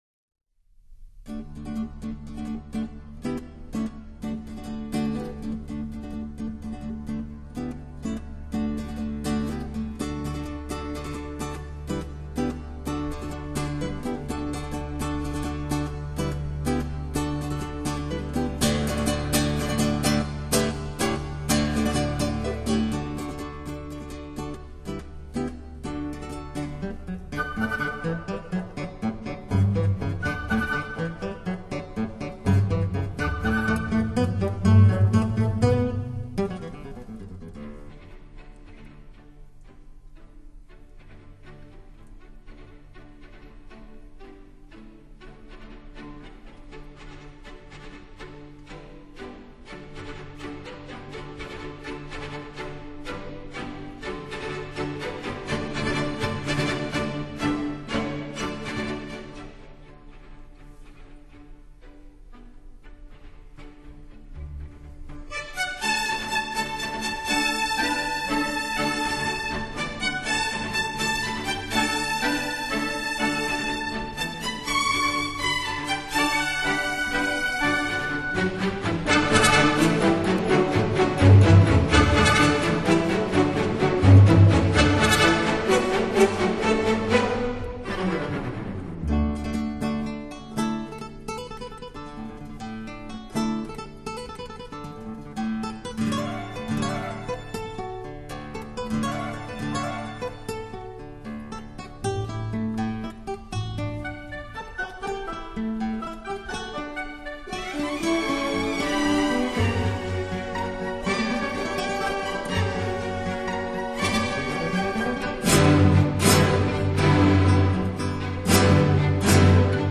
也是吉他音乐最具说服力的代表作